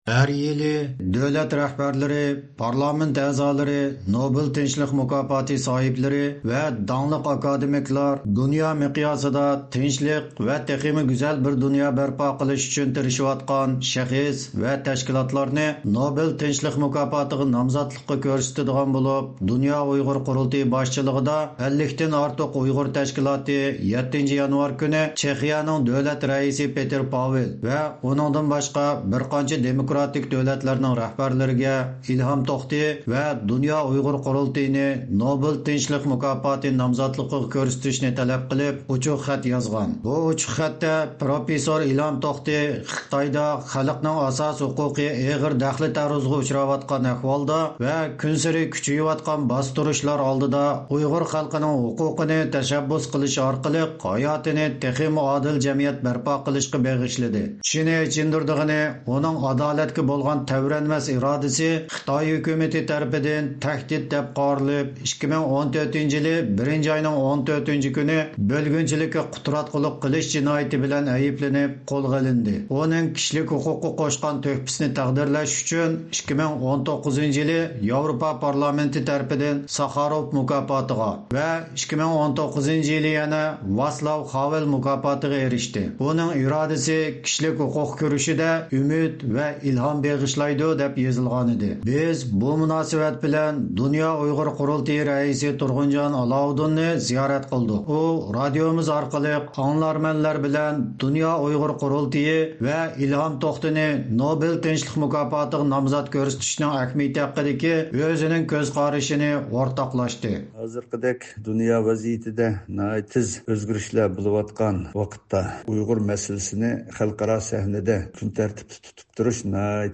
دۇنيا ئۇيغۇر قۇرۇلتىيى سابىق رەئىسى دولقۇن ئەيسا بۇ ھەقتە توختالدى.